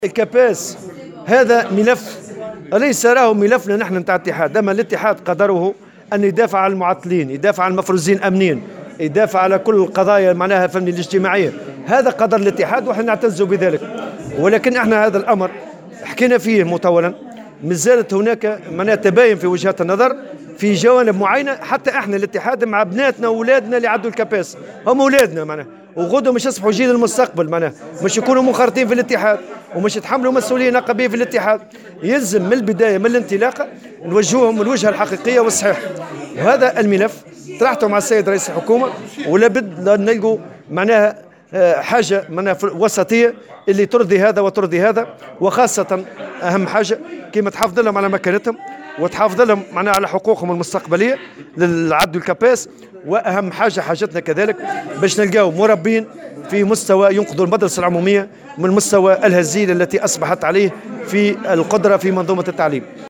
وصرّح لمراسل "الجوهرة أف أم" على هامش زيارة أداها اليوم إلى سيدي بوزيد لاحياء ذكرى 17 ديسمبر 2010، أنه طرح الملف على أنظار رئيس الحكومة لإيجاد حل مناسب يرضي الطرفين، بحسب تعبيره، مؤكدا حرص الاتحاد على ضمان حقوق أساتذة الكاباس على حقوقهم وعلى اعدادهم الجيد لأداء مهمتهم التربوية.